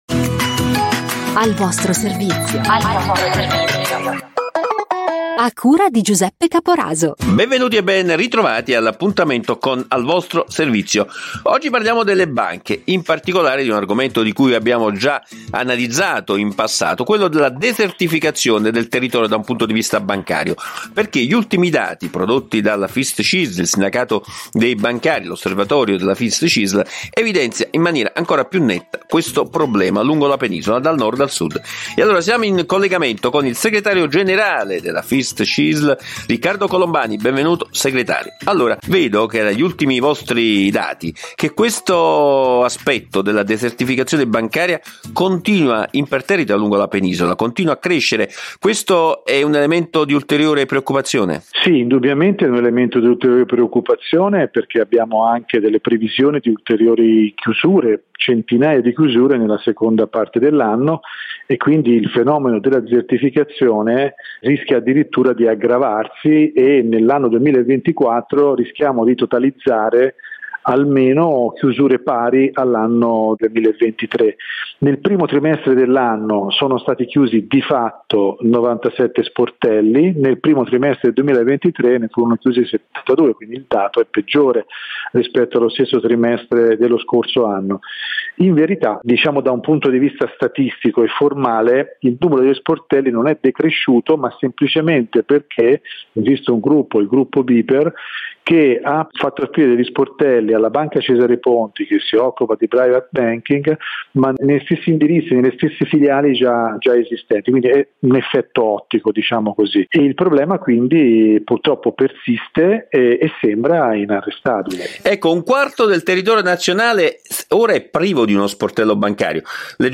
trasmissione “Al vostro servizio